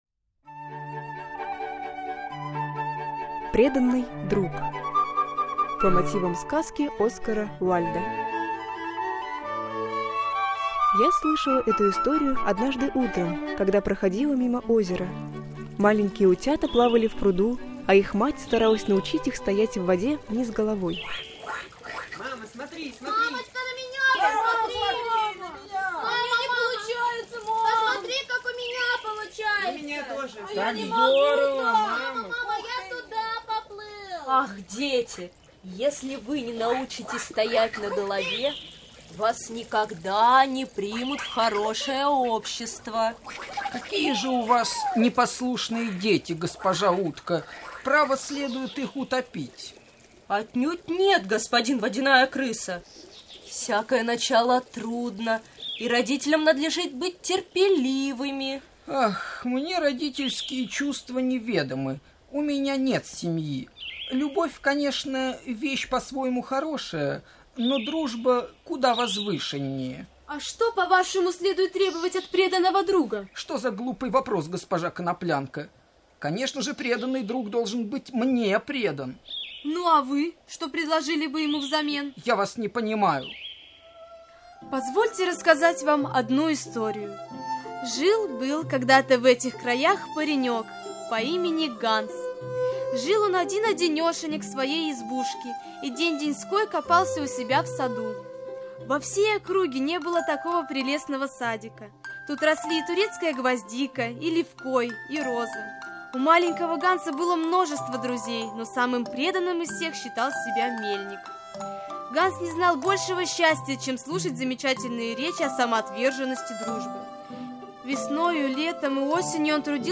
Московский городской конкурс "Чтение вслух"